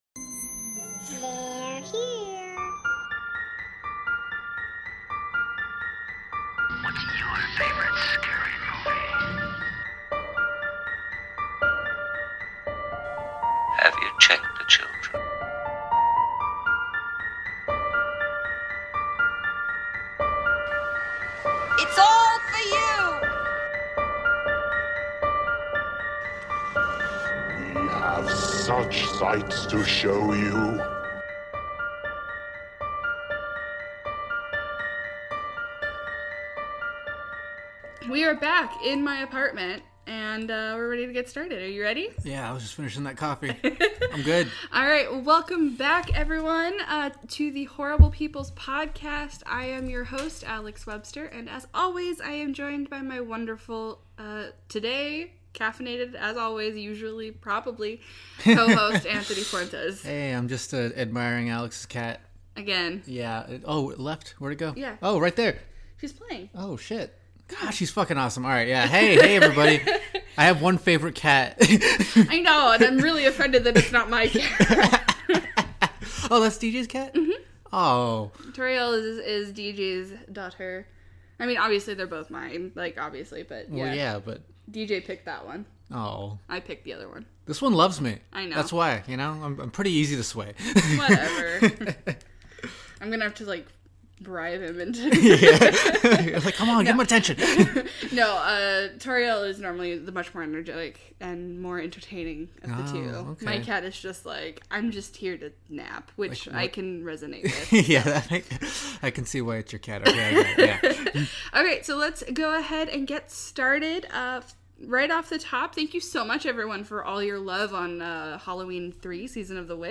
Forgive the cat noises, they just wanted to be apart of something.